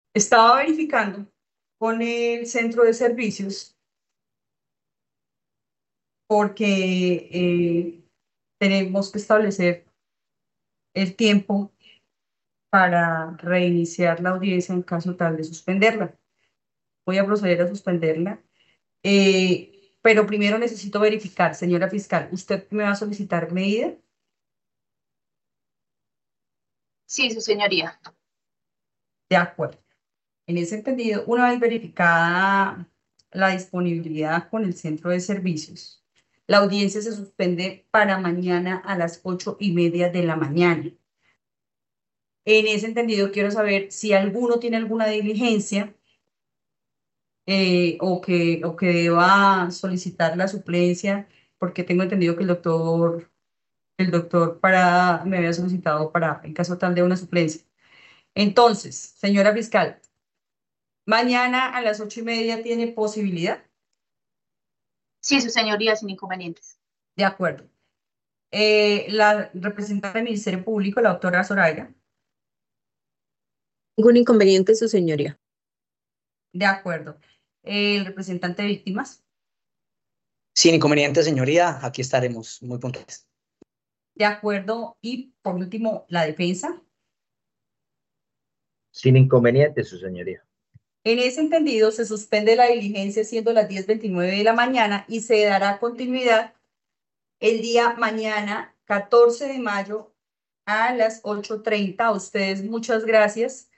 Juez